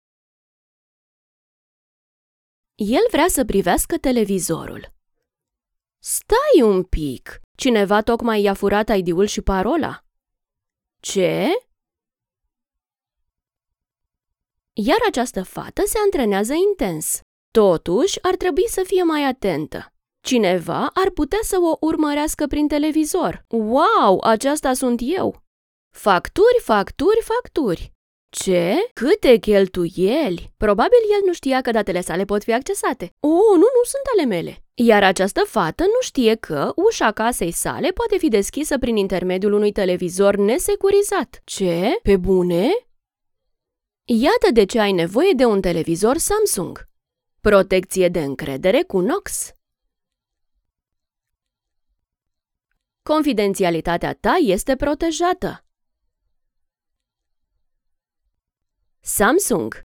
Reliable, Friendly, Warm, Soft, Corporate
Commercial